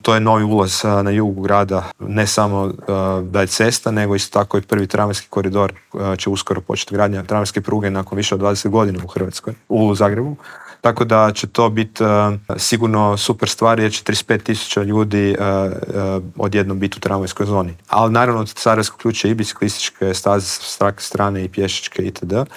ZAGREB - Blokovsko parkiranje, nikad više automobila u metropoli - najavljena je izgradnja nove infrastrukture - gradit će se Jarunski most, proširiti tramvajska mreža na Sarajevskoj cesti - što nas sve čeka u idućim godinama u metropoli u Intervjuu tjedna Media servisa rekao nam je zagrebački gradonačelnik Tomislav Tomašević - otkrio je i kada možemo očekivati završetak novog maksimirskog stadiona.